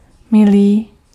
Ääntäminen
Vaihtoehtoiset kirjoitusmuodot (vanhentunut) deare (vanhahtava) deere Synonyymit darling sweetheart babe sister ducks expensive pricey precious cherished pricy sugar baby bae Ääntäminen RP : IPA : /dɪə/ US : IPA : [dɪə] GenAm: IPA : /dɪɹ/ Scotland: IPA : /diːr/